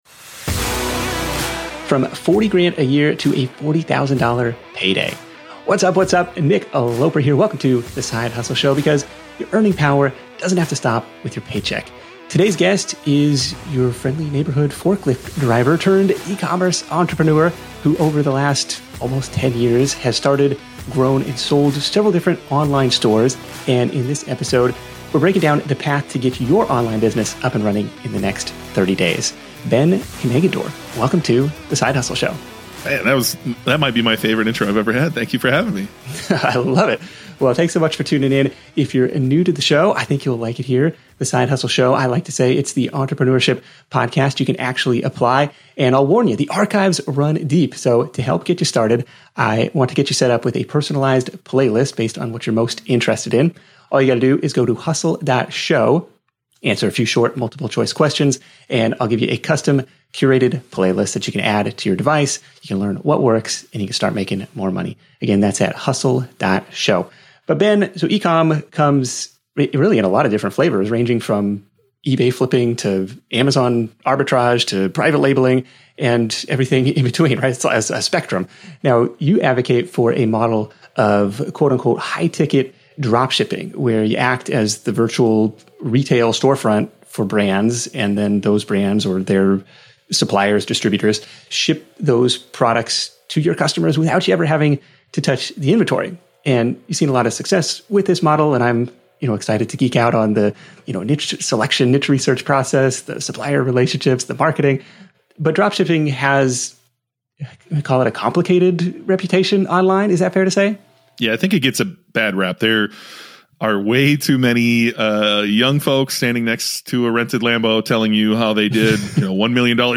Today’s guest is your friendly neighborhood forklift driver turned e-commerce entrepreneur.
Side Hustle Show interview